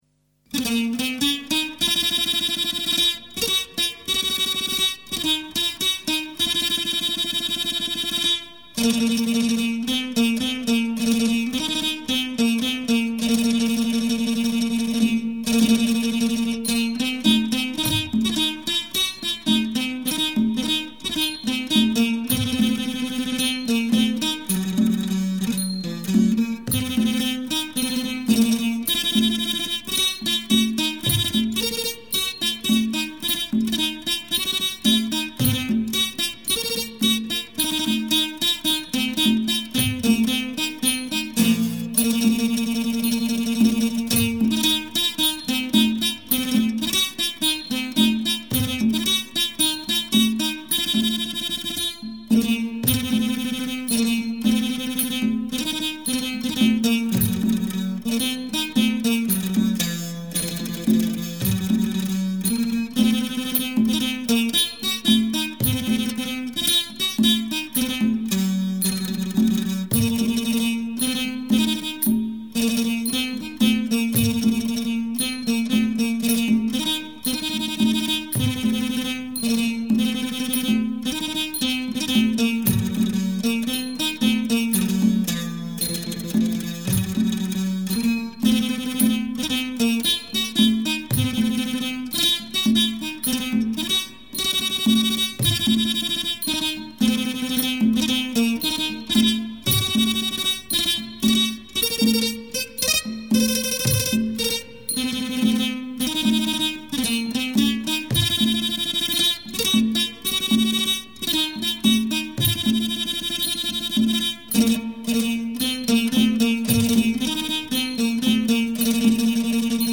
[3/5/2008]Thai Classical Music (Instrumental)
Thai Classical Music